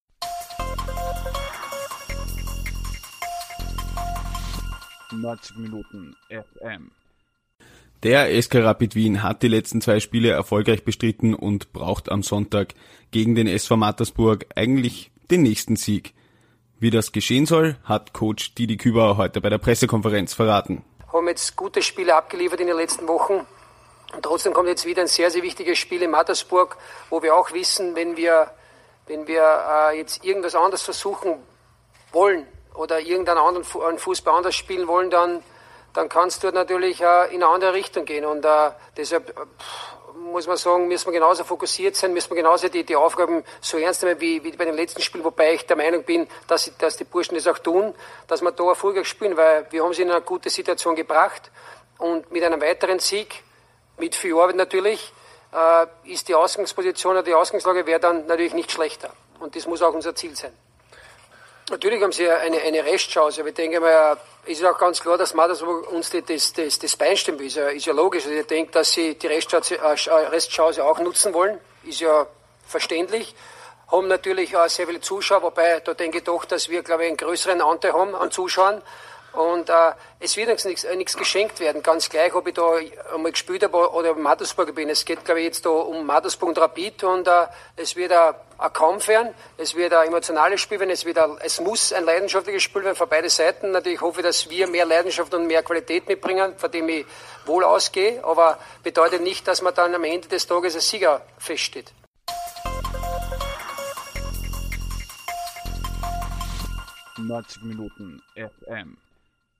Die besten Aussagen von Rapid-Trainer Didi Kühbauer vor dem Spiel